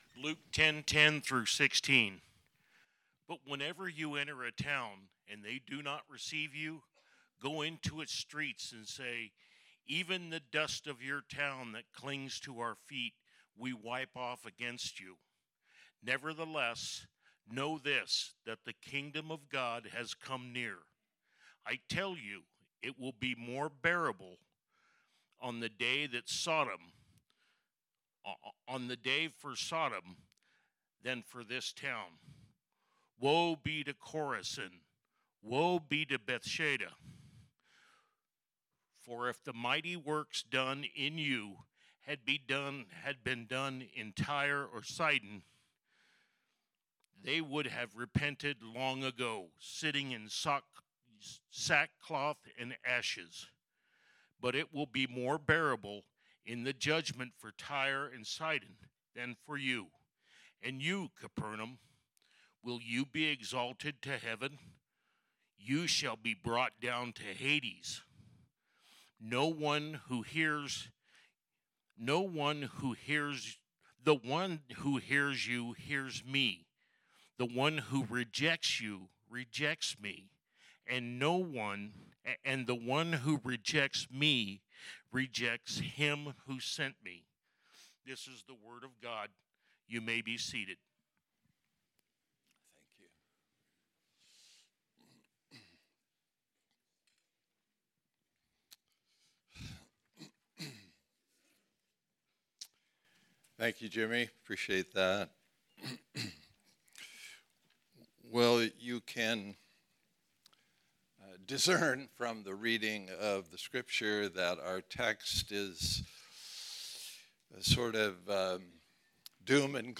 Series: The Gospel of Luke Type: Sermon